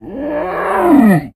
bdog_idle_5.ogg